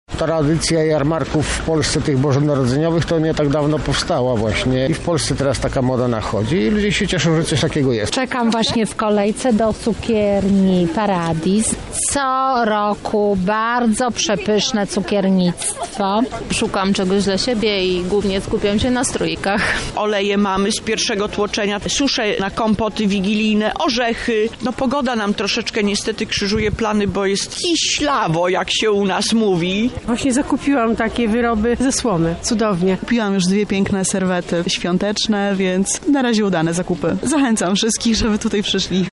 O swoich wrażeniach mówią zarówno uczestnicy jak i wystawcy.